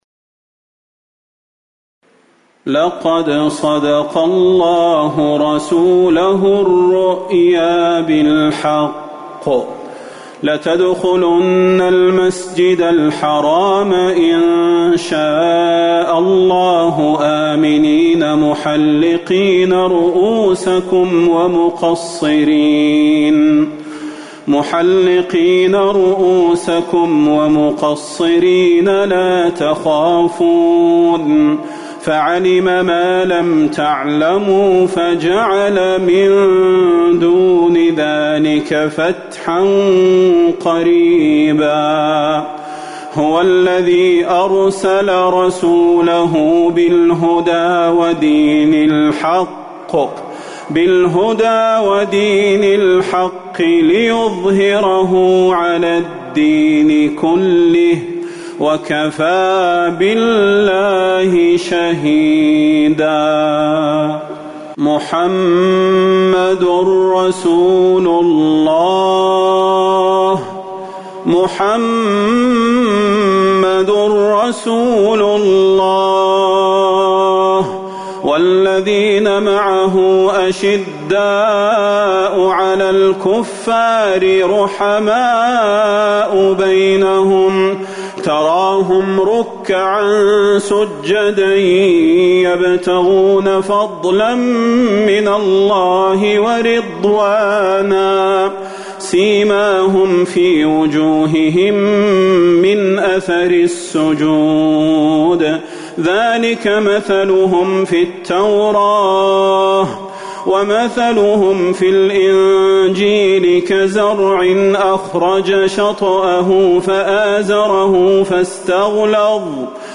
عشاء 4-1-1441هـ من سورة الفتح | Isha prayer from Surat Al-Fath > 1441 🕌 > الفروض - تلاوات الحرمين